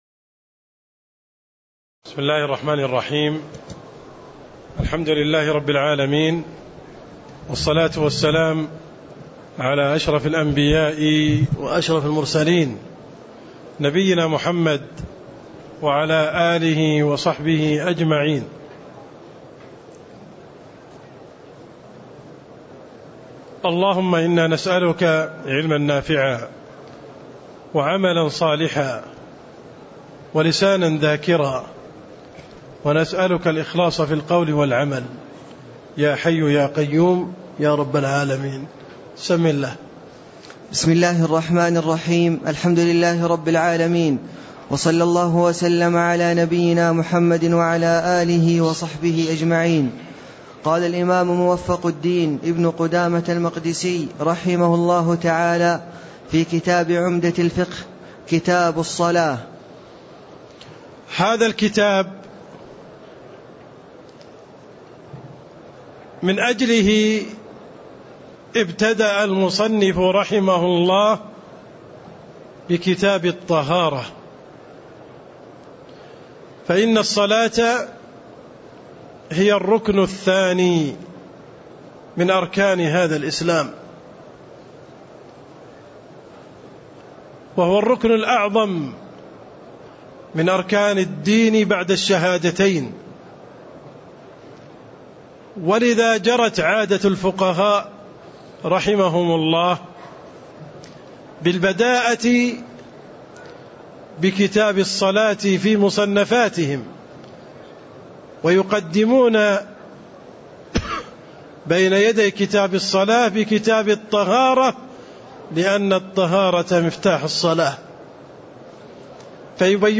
تاريخ النشر ٦ رمضان ١٤٣٥ هـ المكان: المسجد النبوي الشيخ: عبدالرحمن السند عبدالرحمن السند مقدمة كتاب الصلاة (01)